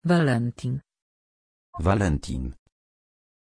Pronunciation of Vallentin
pronunciation-vallentin-pl.mp3